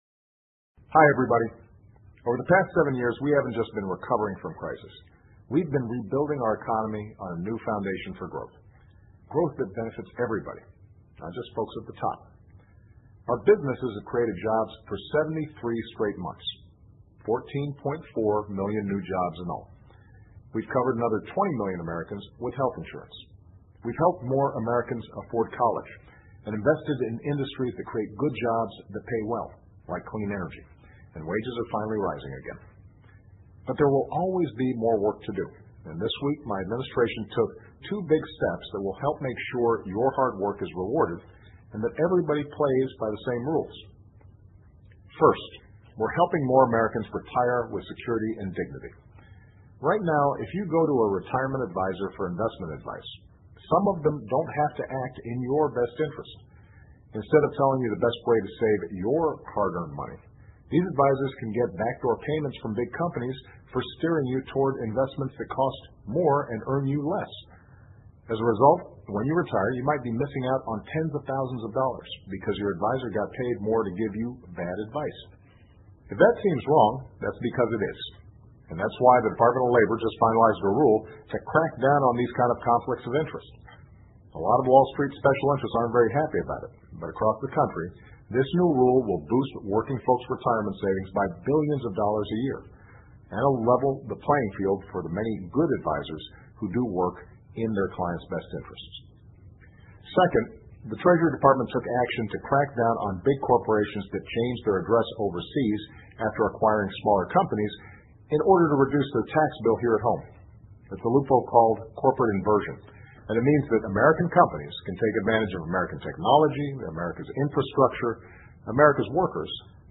奥巴马每周电视讲话：总统呼吁遵守同样的秩序规则 听力文件下载—在线英语听力室